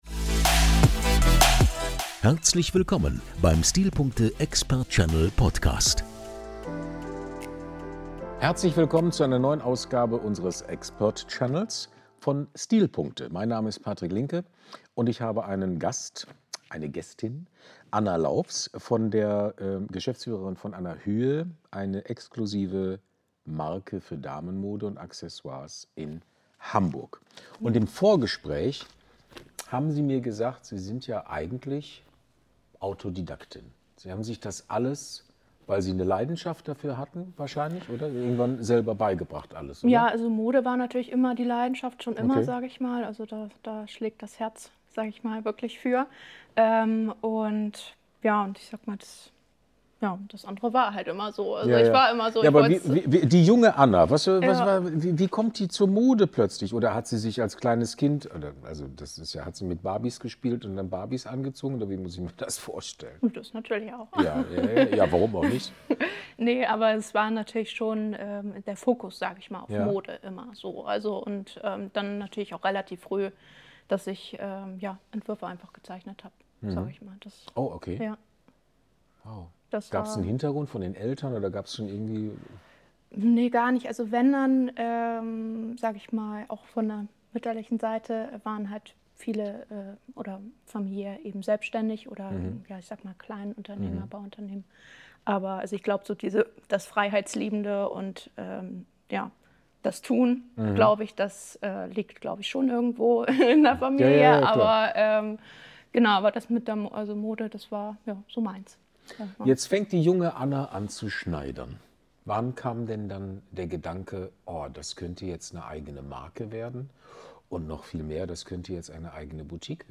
Das klare Design, die präzise Verarbeitung und der Fokus auf Qualität zeichnen ihre Kollektionen aus. Im Gespräch